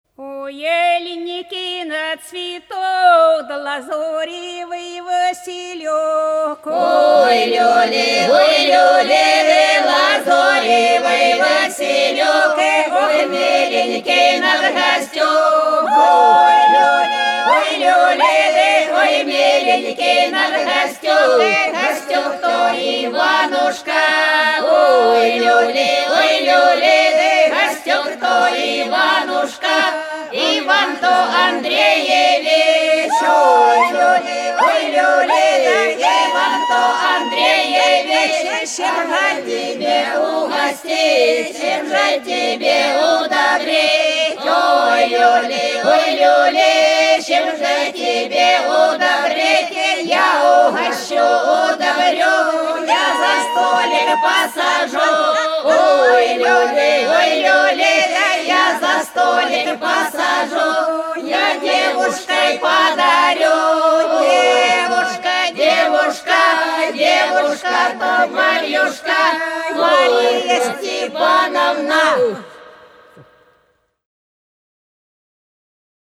По-над садом, садом дорожка лежала Ох, аленький мой цветок (хороводная (с.Фощеватово, Белгородская область)
18_Ох,_аленький_мой_цветок_(хороводная).mp3